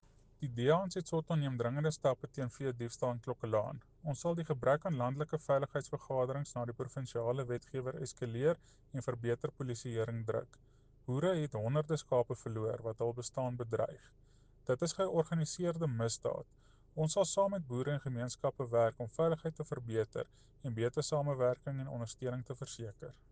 Afrikaans soundbites by Cllr Jose Coetzee and